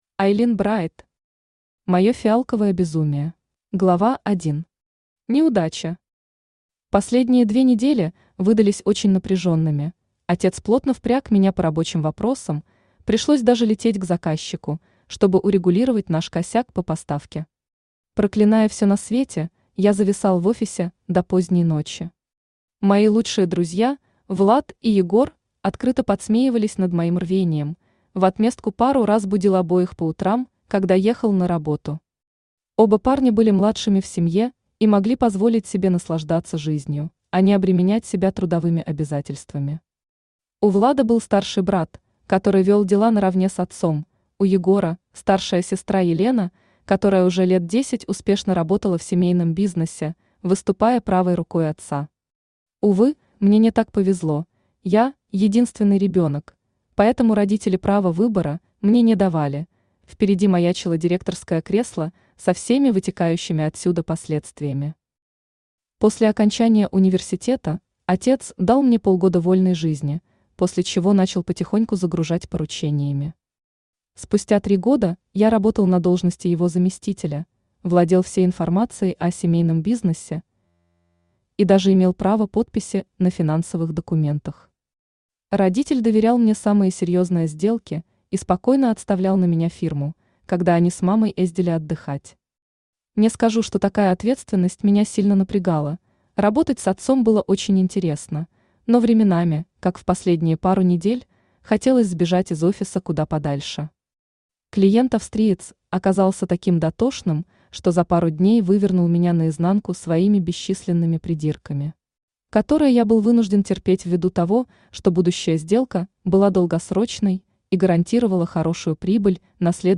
Aудиокнига Мое фиалковое безумие Автор Айлин Брайт Читает аудиокнигу Авточтец ЛитРес. Прослушать и бесплатно скачать фрагмент аудиокниги